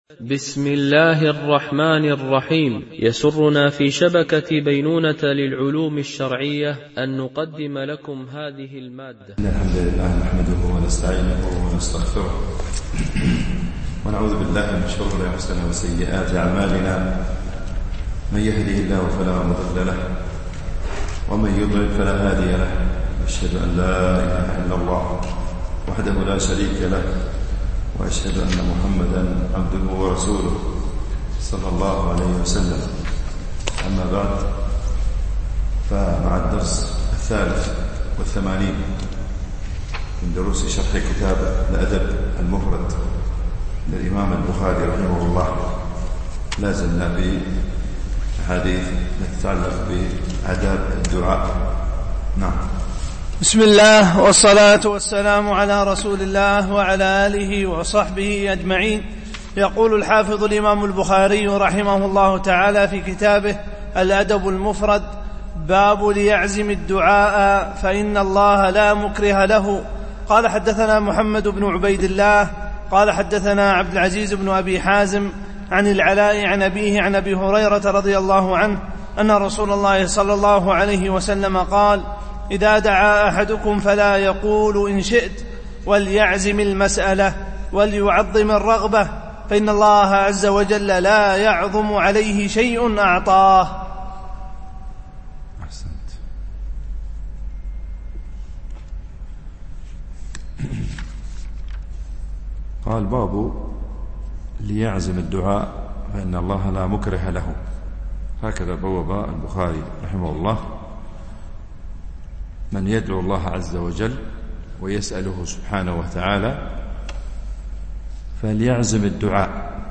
شرح الأدب المفرد للبخاري ـ الدرس 83 ( الحديث 607 -608)
MP3 Mono 22kHz 32Kbps (CBR)